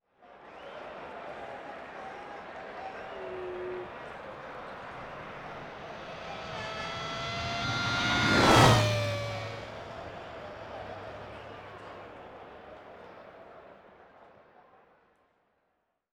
Pasada de un coche en una carrera de formula 3000
Sonidos: Deportes
Sonidos: Transportes